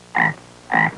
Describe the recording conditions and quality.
Download a high-quality bullfrog sound effect.